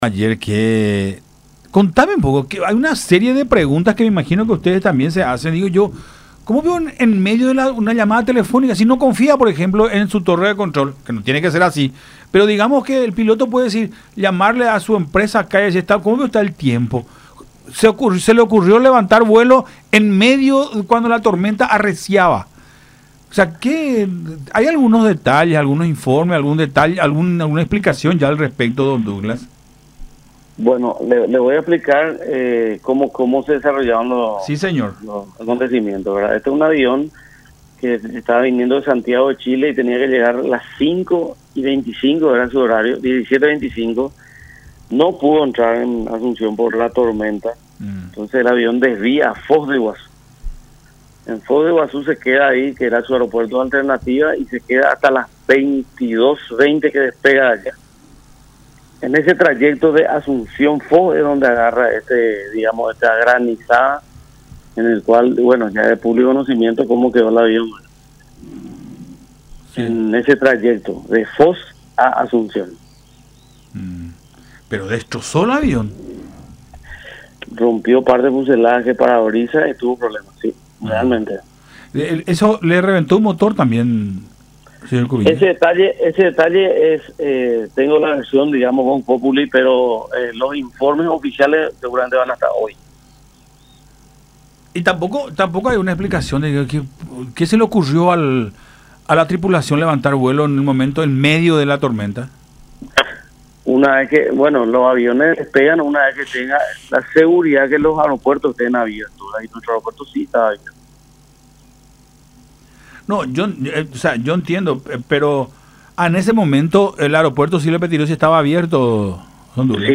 Tuvo el problema aproximadamente 15 minutos antes de llegar, prácticamente a mitad de viaje, ya que 30 minutos de vuelo nomás es entre Foz y Asunción”, explicó Douglas Cubilla, titular de la Dirección Nacional de Aeronáutica Civil (DINAC), en diálogo con Nuestra Mañana por Unión TV y radio La Unión, señalando que a raíz justamente de ese último evento es que la aeronave, perteneciente a la aerolínea Latam, quedó perforada en su nariz.